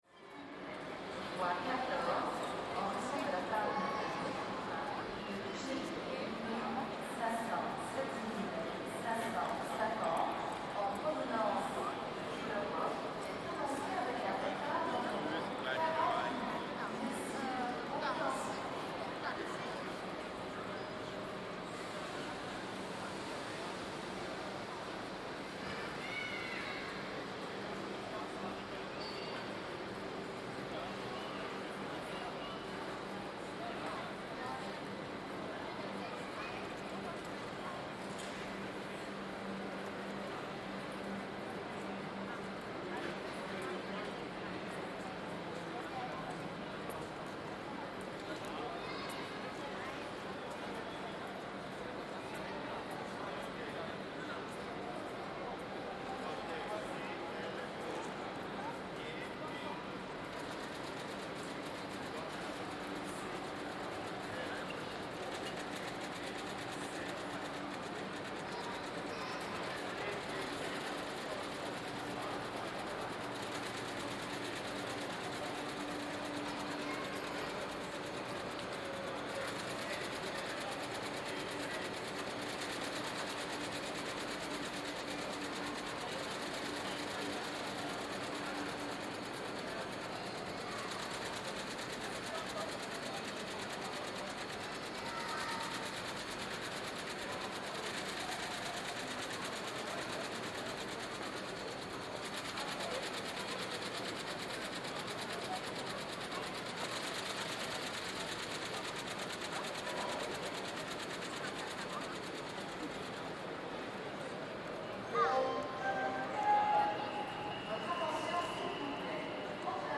The lost sound of the split flat departure board
Each time the board would update, a wonderful flipping noise would be heard. This recording captures exactly that sound, recorded at Gare du Nord in 2012." Sony PCM D50 + Soundman OKM II binaural mics + A3 adapter